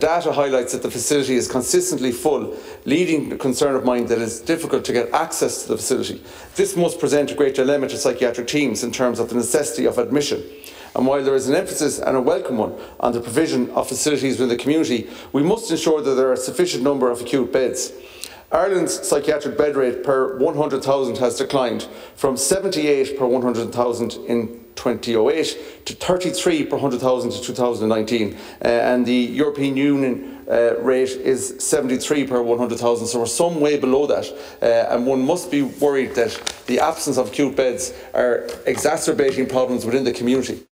Fianna Fail Deputy Connolly told the Dáil that data shows the mental health unit is consistently full – and that’s a serious problem.